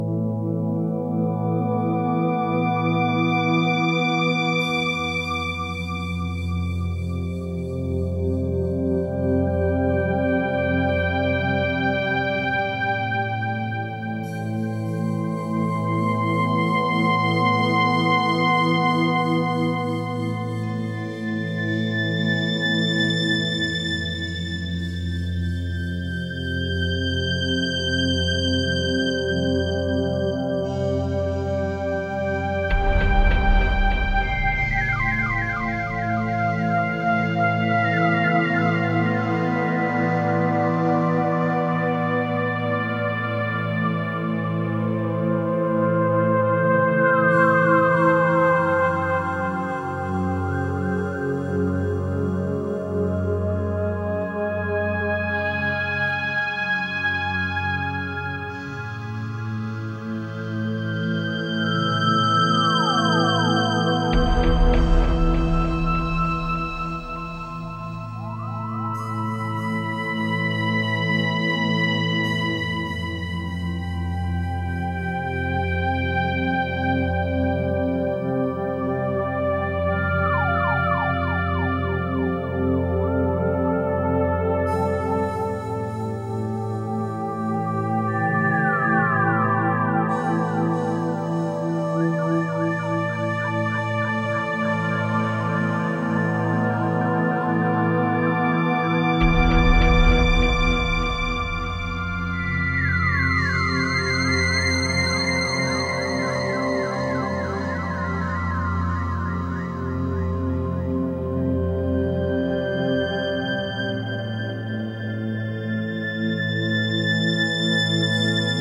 is a stunning and deeply emotive ambient work
Electronix Ambient